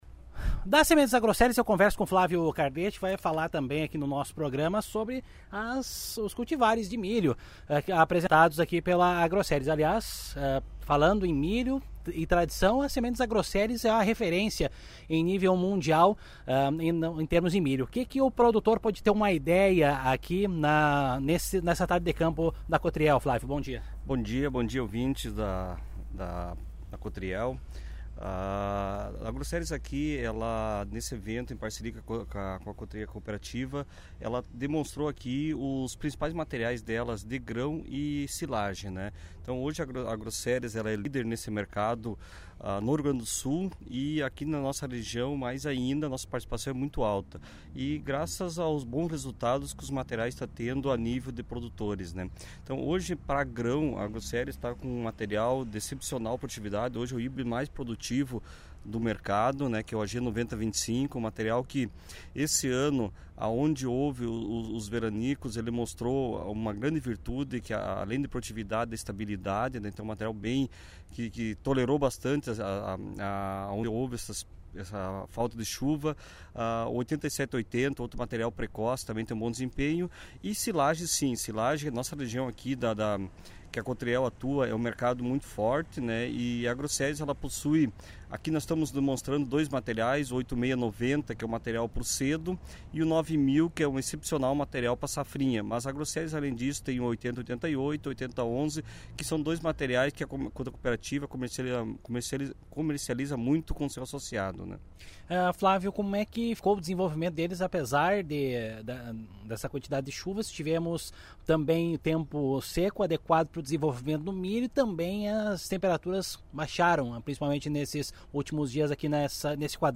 aqui a entrevista